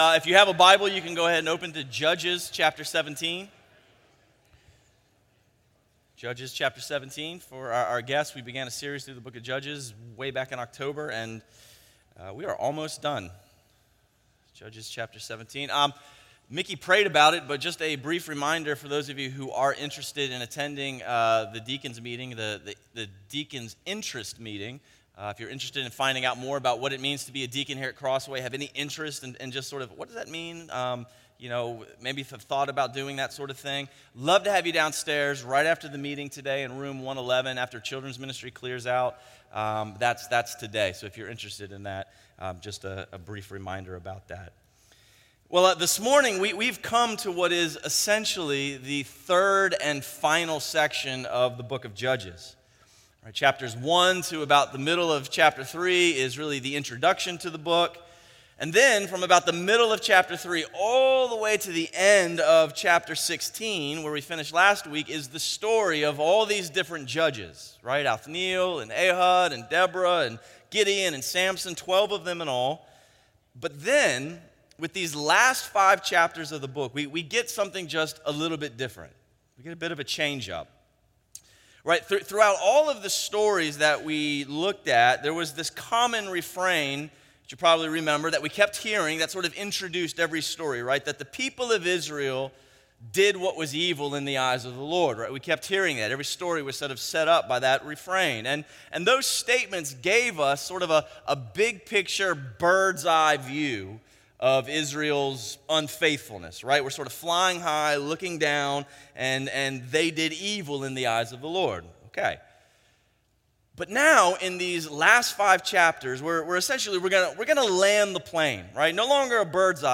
A message from the series "Behold Him."